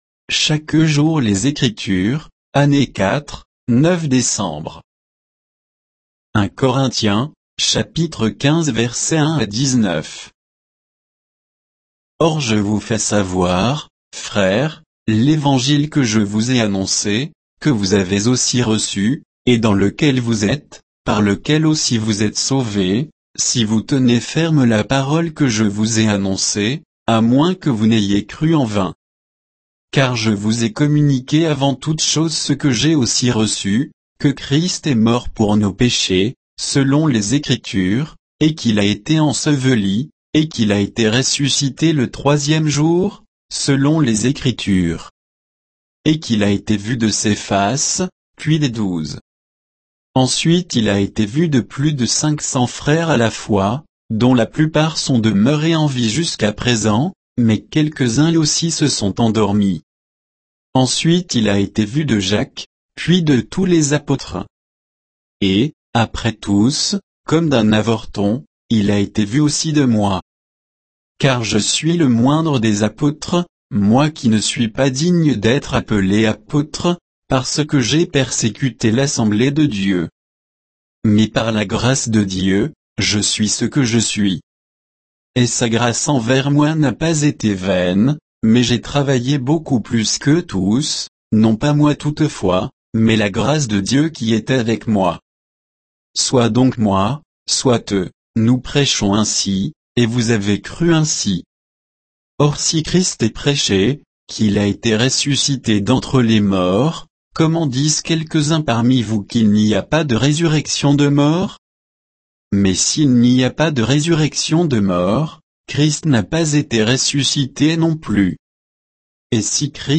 Méditation quoditienne de Chaque jour les Écritures sur 1 Corinthiens 15